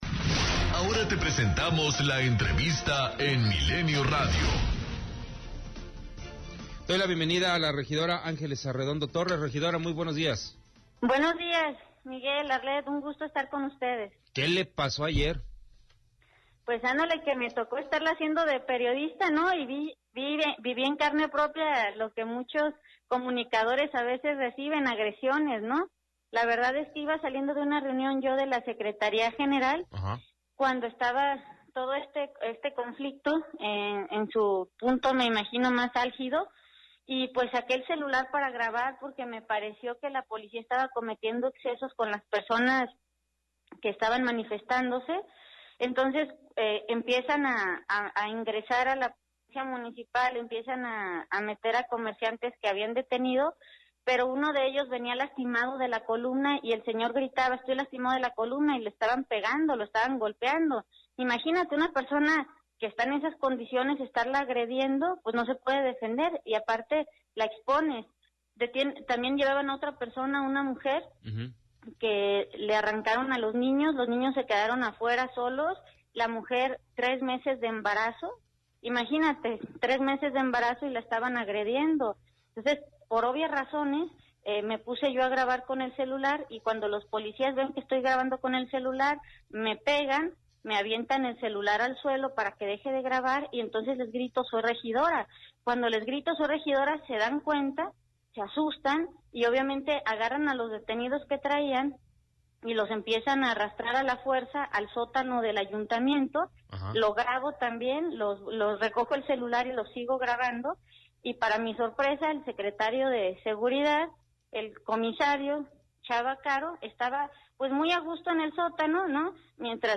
Tras la violenta manifestación que se dio ayer por parte de los comerciantes ambulantes a las afueras del ayuntamiento de Guadalajara varias personas resultaron lesionadas tal es el caso de la regidora del PRI Ángeles Arredondo, quien informó en entrevista para MILENIO RADIO que interpondrá una denuncia ante derechos humanos en contra de los elementos de la policía de Guadalajara por los que fue agredida, en este contexto, aseguró que estas manifestaciones se seguirán registrando hasta que el Presidente Municipal Enrique Alfaro presente alternativas reales a todos los comerciantes.